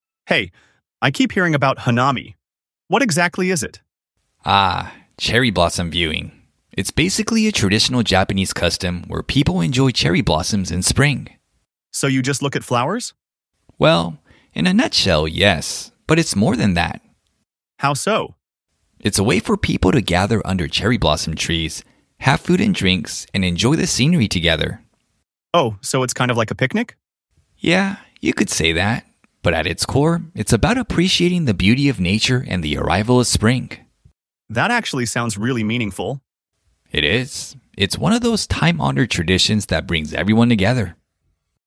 2. Dialog